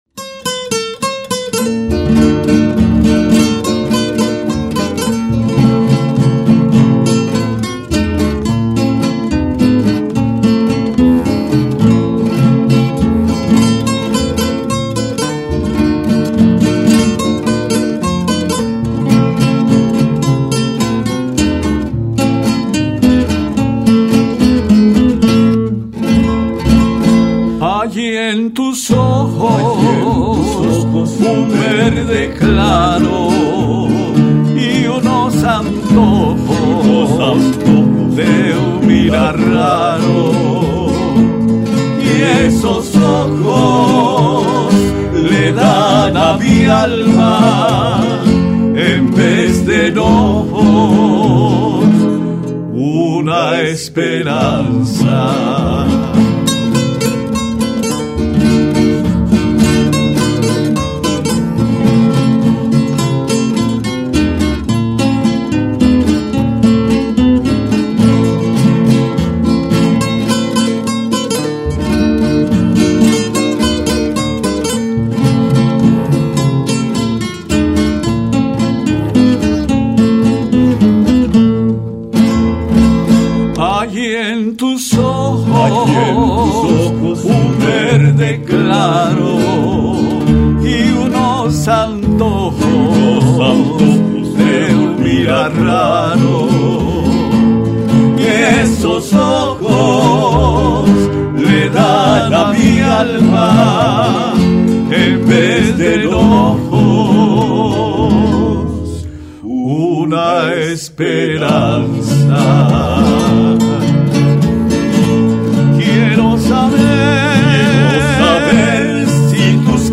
Cortinas musicales